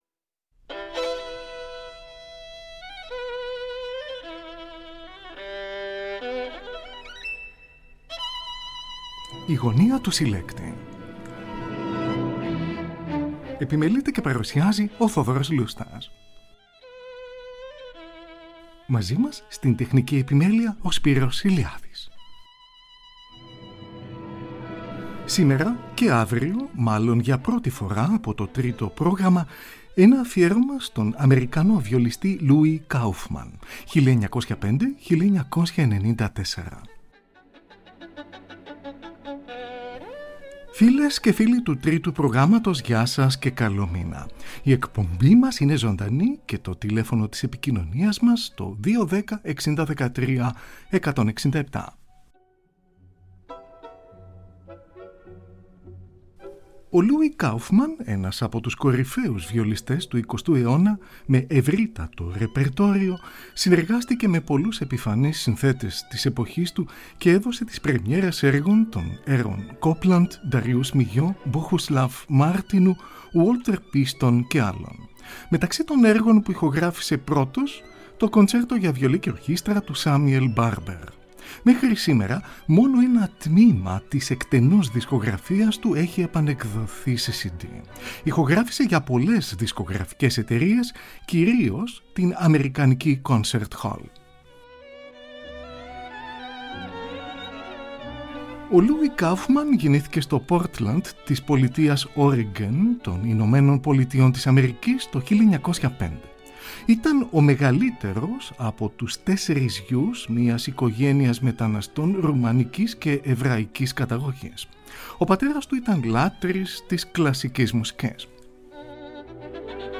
Ακούγονται συνθέσεις των Antonio Vivaldi, Camille Saint-Saëns και Pyotr Ilyich Tchaikovsky.